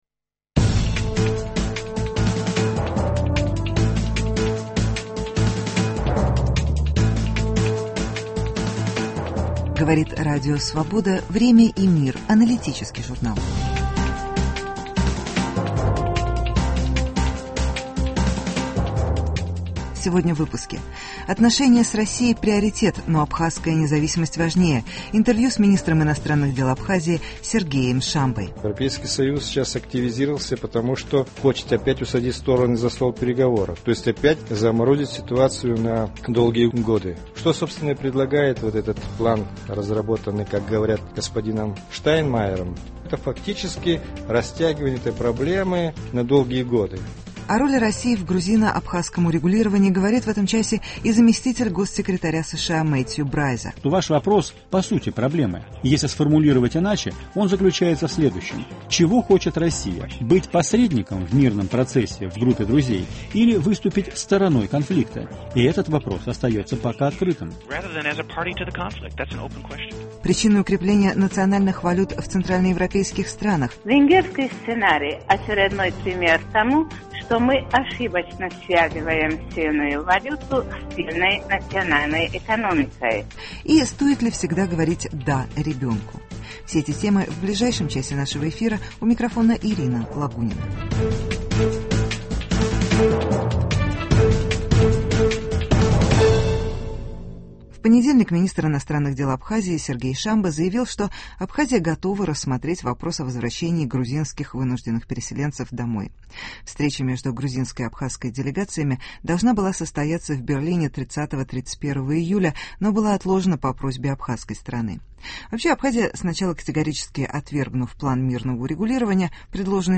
Интервью с министром иностранных дел Абхазии Сергеем Шамбой. Заместитель госсекретаря США Мэтью Брайза о роли России в грузино-абхазском урегулировании Почему укрепляются валюты центральноевропейских стран.